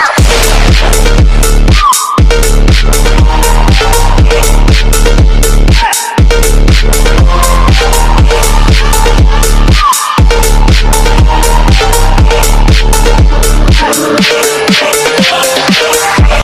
Category: TikTok Soundboard